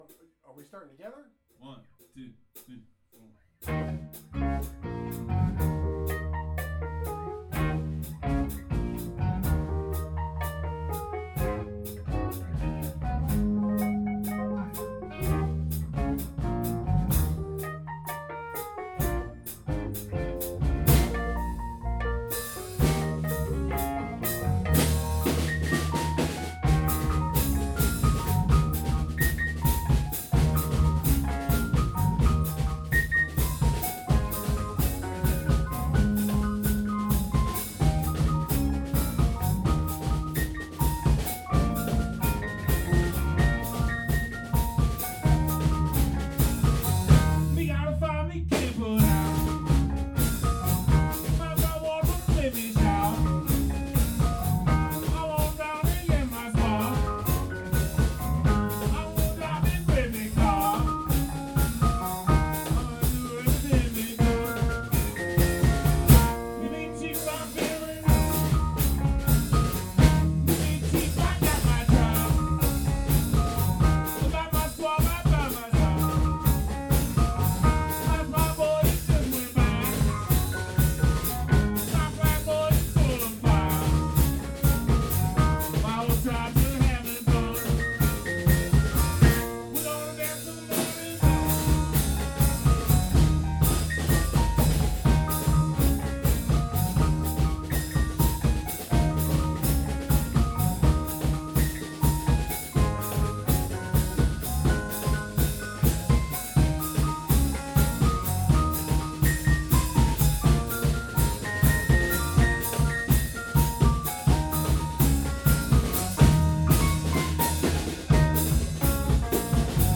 by Professor Longhair
Eb